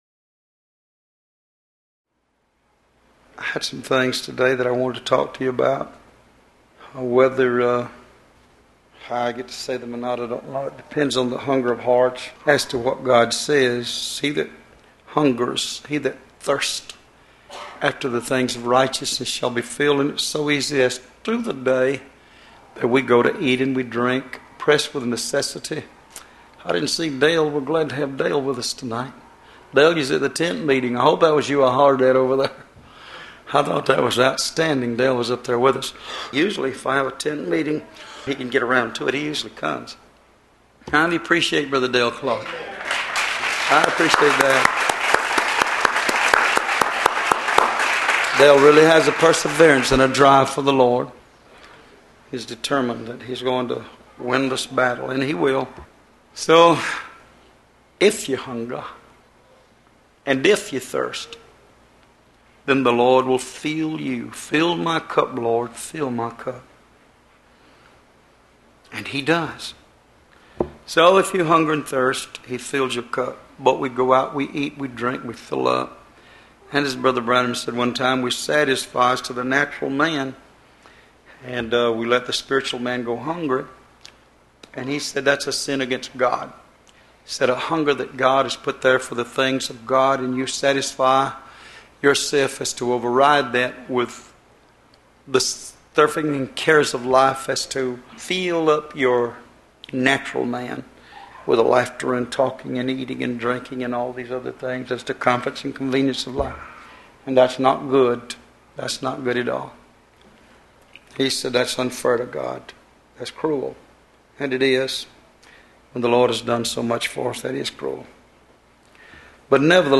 Location: Love’s Temple in Monroe, GA USA